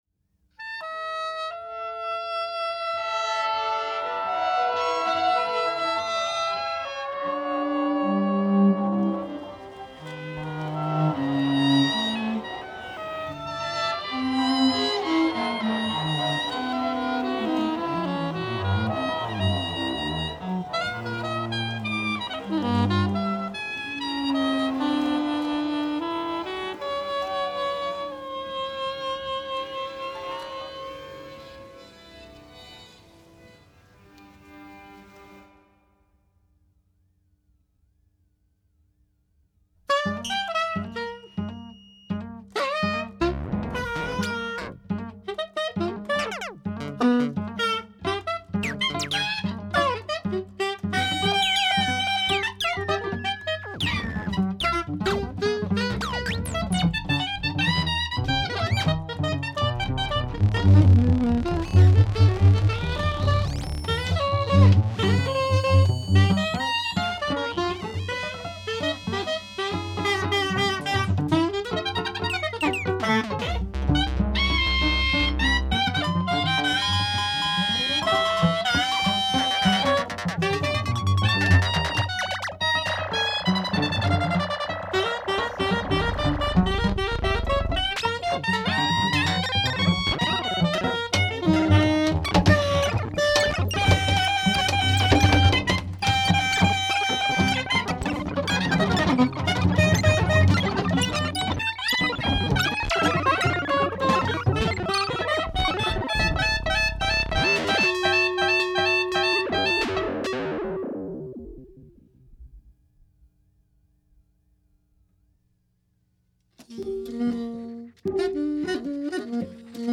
really a kind of suite made up of many short episodes.